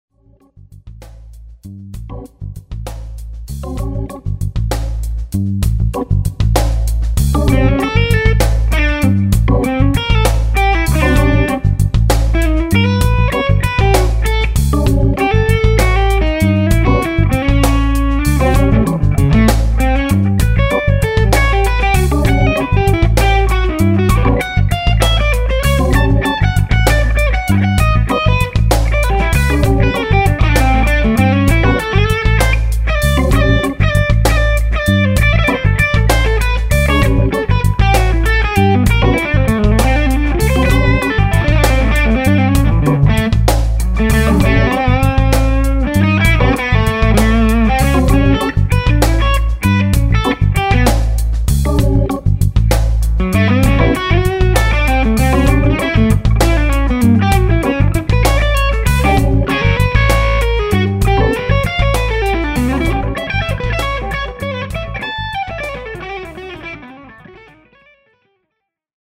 Here is another Royer BM take
Really cool big round sound 8)